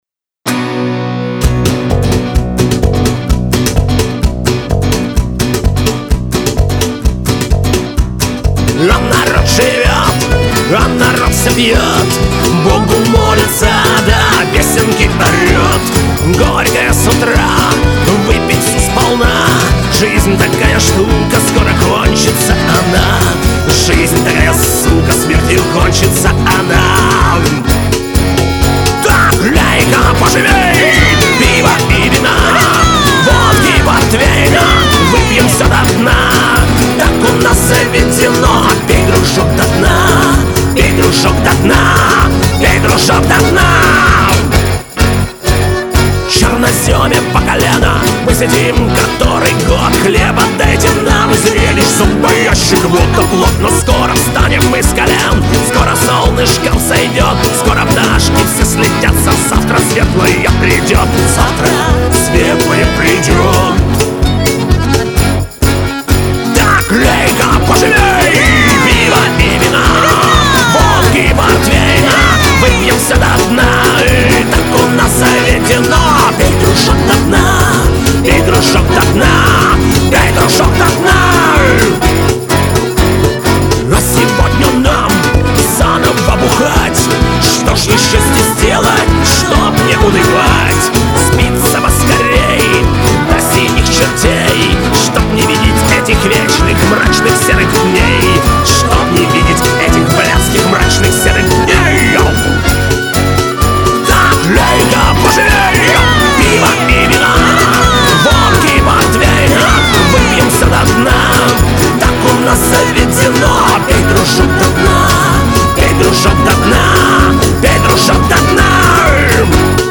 Жанр: фолк-панк
вокал, гитары
перкуссия, скиффл-инструменты.
ритм-гитара.
бас.
джа-кахон.
баян.
скрипка.